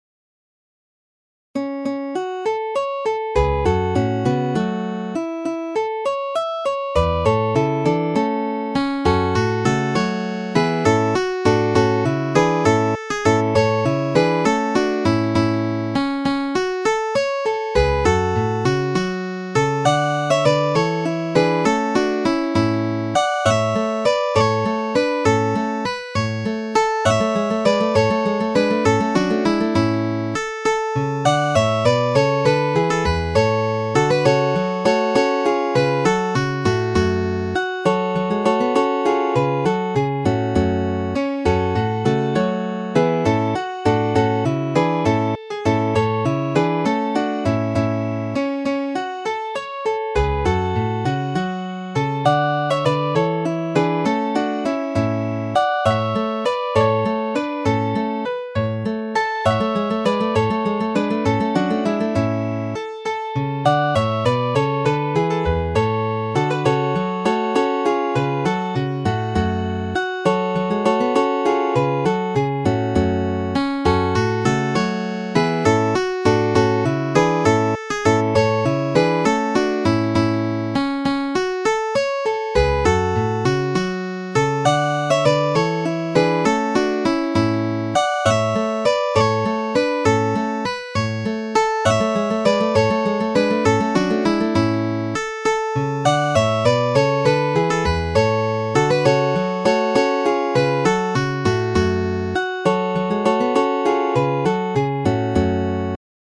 Midi File, Lyrics and Information to What do ye think o' Geordie noo?